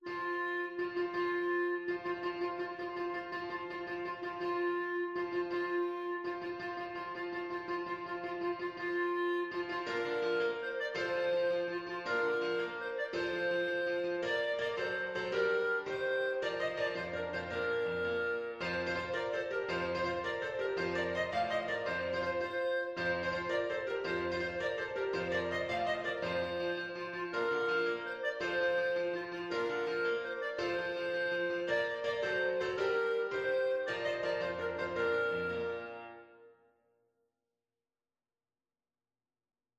Clarinet
Bb major (Sounding Pitch) C major (Clarinet in Bb) (View more Bb major Music for Clarinet )
With energy .=c.110
6/8 (View more 6/8 Music)
F5-F6
Classical (View more Classical Clarinet Music)